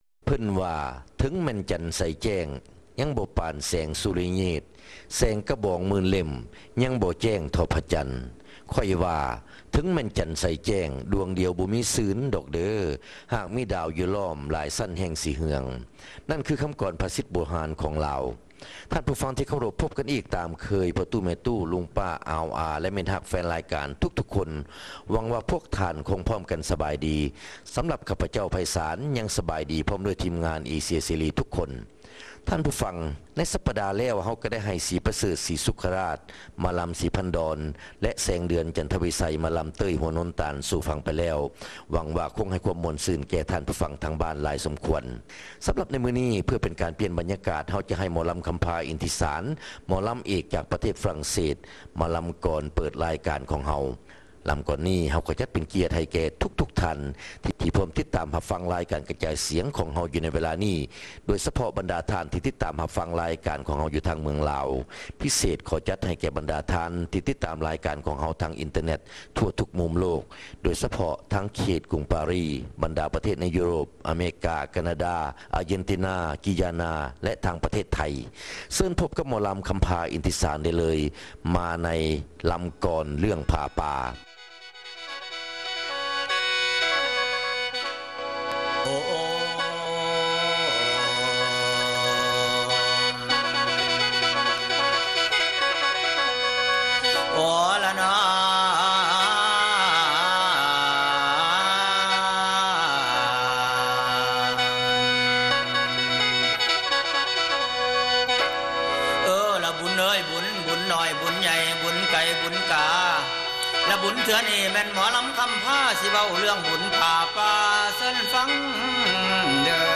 ຣາຍການໜໍລຳ ປະຈຳສັປະດາ ວັນທີ 18 ເດືອນ ພືສະຈິກາ ປີ 2005